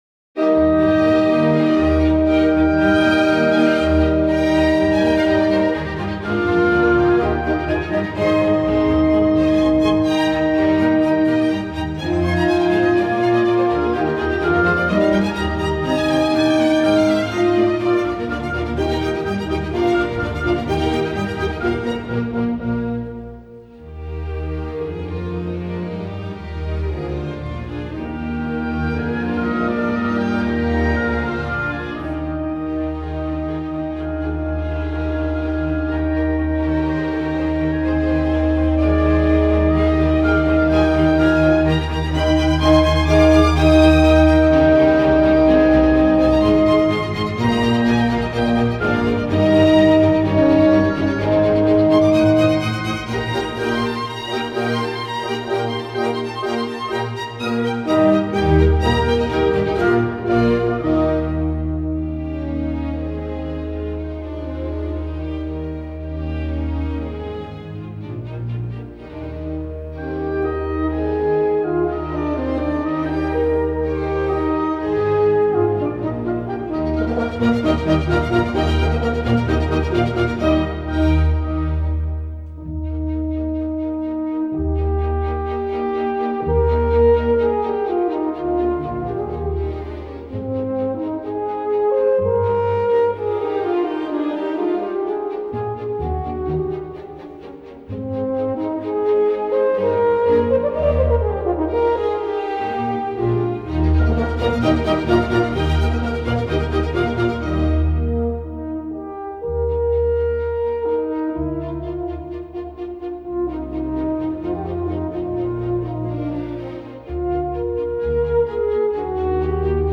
Orchestra
Style: Classical
horn